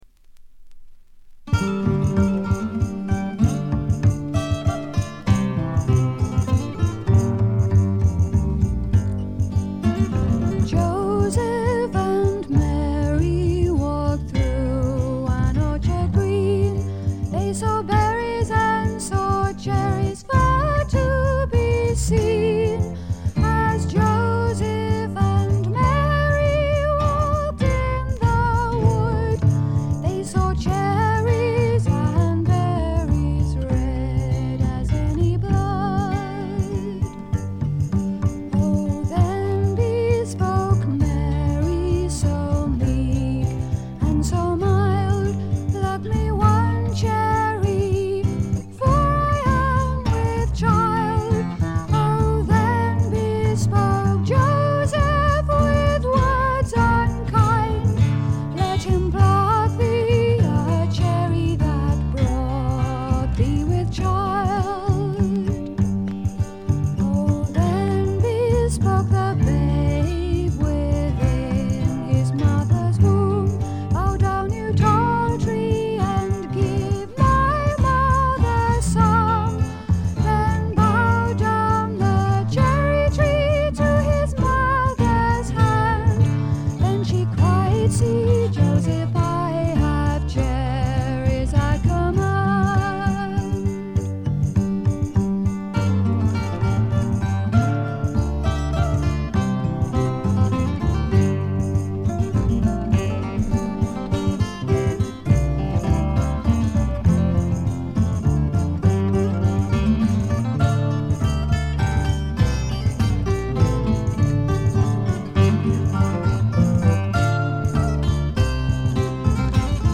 部分試聴ですが軽微なノイズ感のみ。
試聴曲は現品からの取り込み音源です。